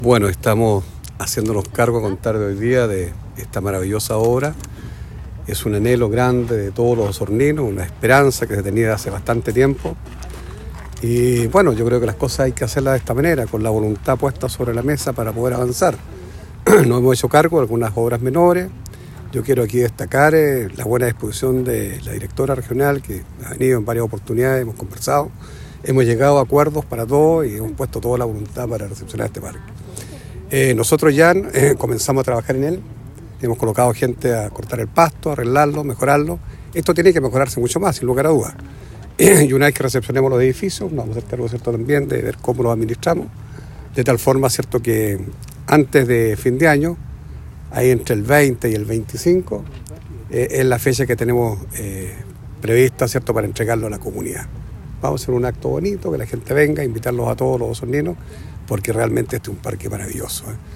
El alcalde Jaime Bertin calificó el traspaso como un hito significativo que permitirá poner este espacio a disposición de la comunidad e informó que personal de operaciones ya trabaja en el lugar con el objetivo de optimizar las instalaciones, con miras a una inauguración oficial en los próximos días.